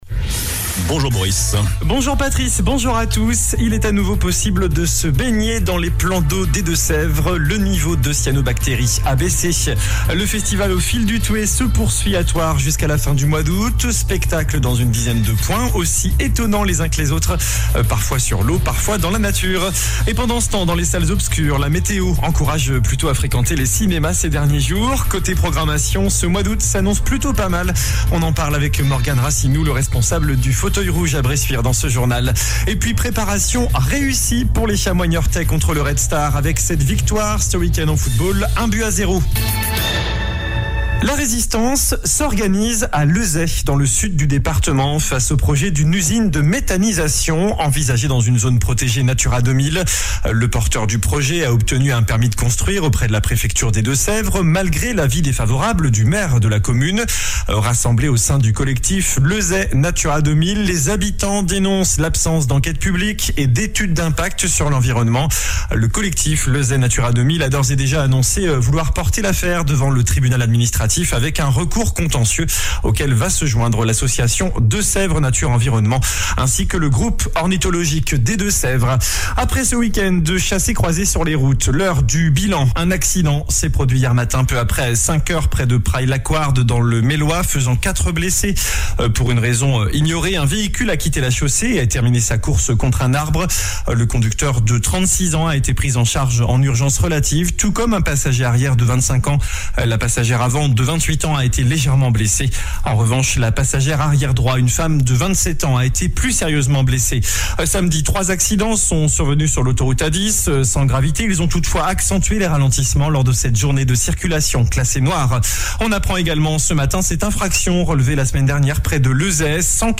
JOURNAL DU LUDI 07 AOÛT ( MIDI )